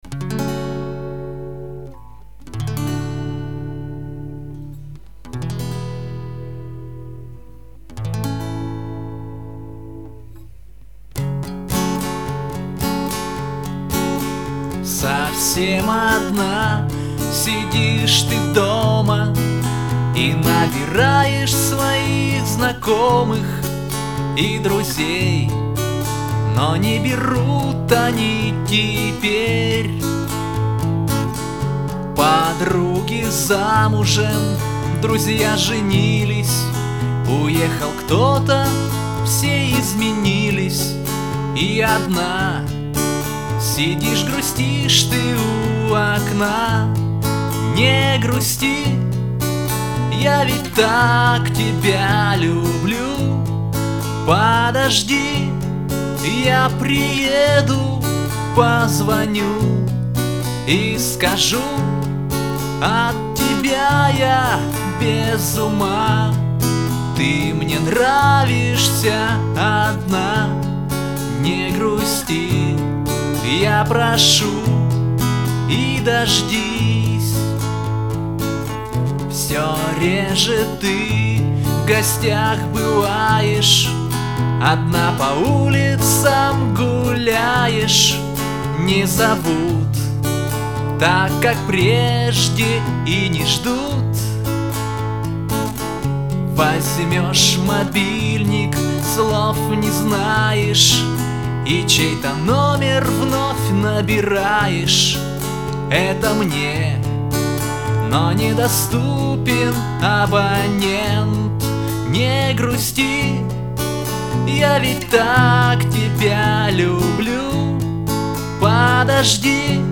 Гитара / Лирические